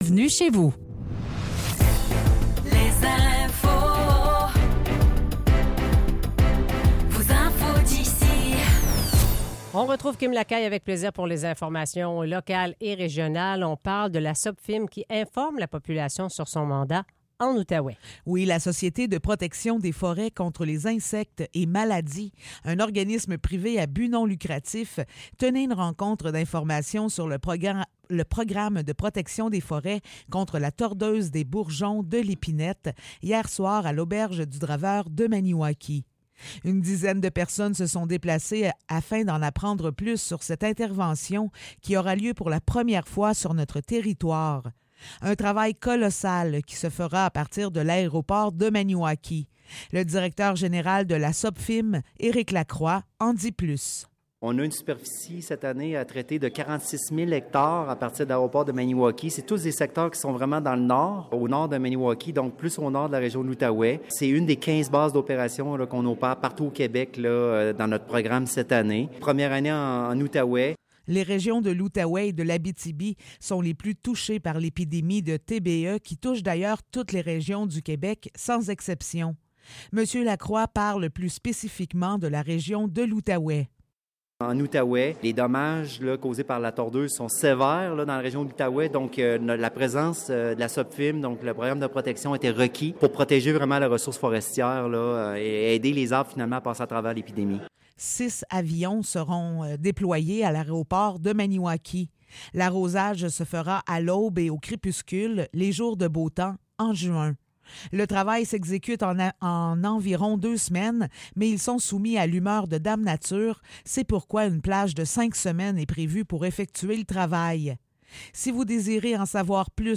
Nouvelles locales - 15 mai 2024 - 8 h